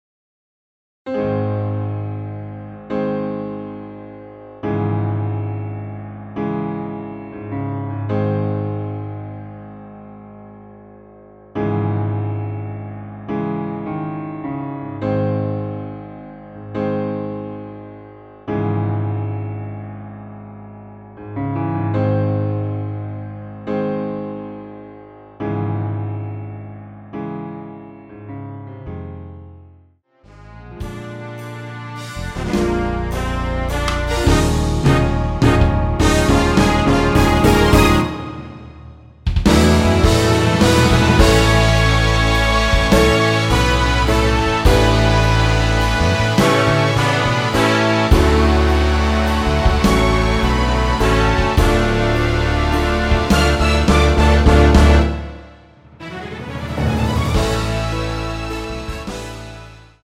Ab
◈ 곡명 옆 (-1)은 반음 내림, (+1)은 반음 올림 입니다.
앞부분30초, 뒷부분30초씩 편집해서 올려 드리고 있습니다.